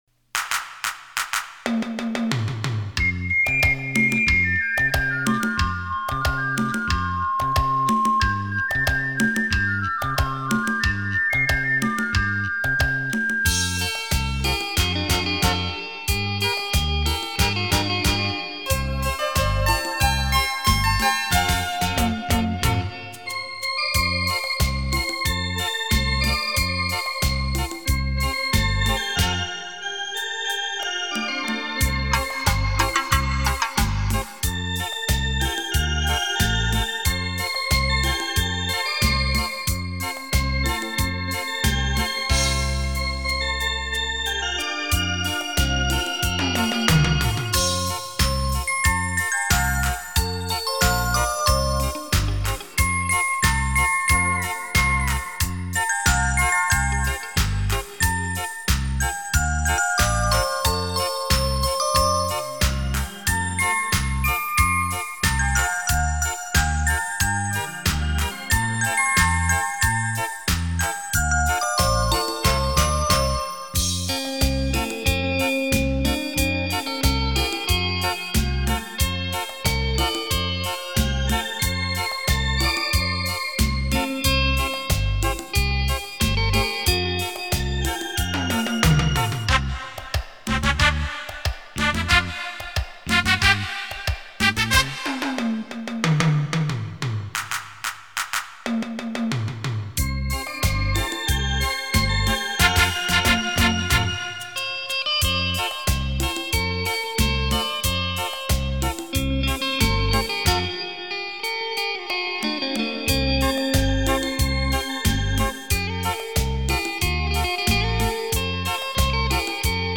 很不错的  电子琴  听过几张  查查 这张有没有